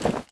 melee_draw_temp1.wav